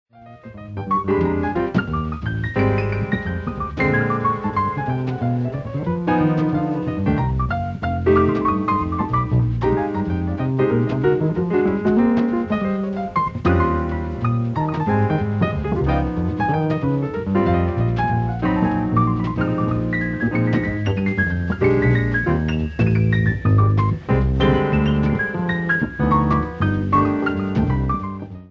SOUNDBOARD RECORDING